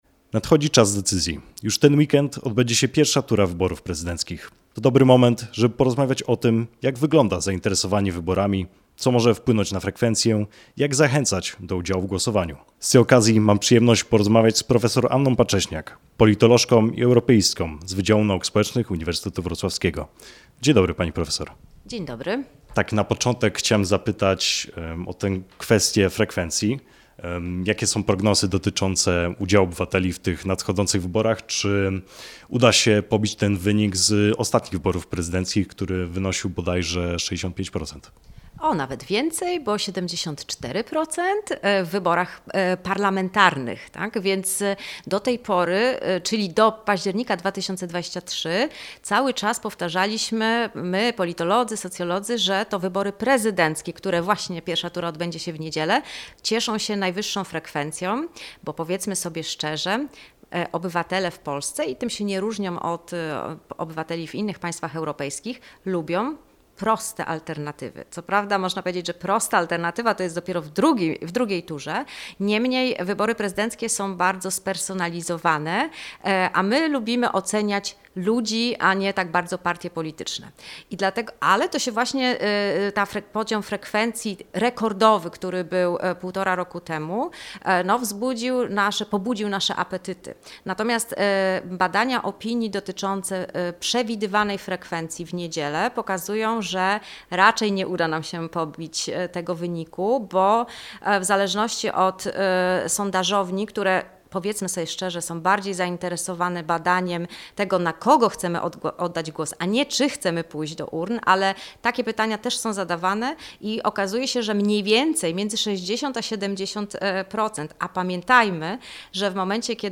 - Radio LUZ